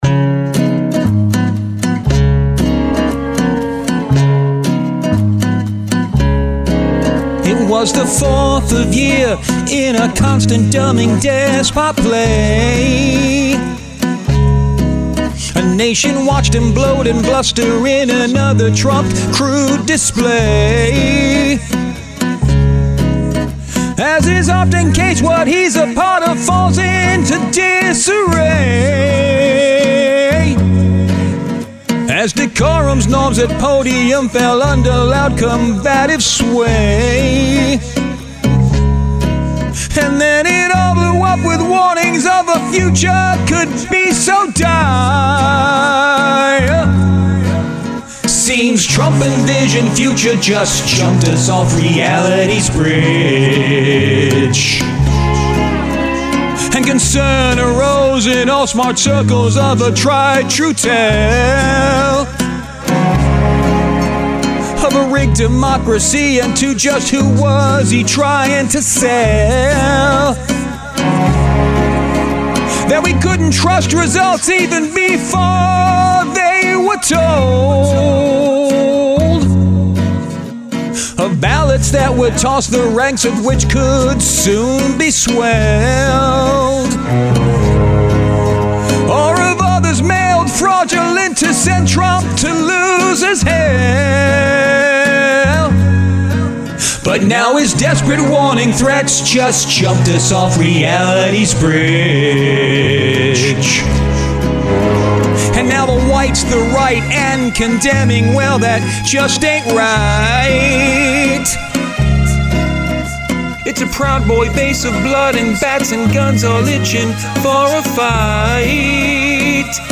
Was feelin’ a bit down home and almost bluesy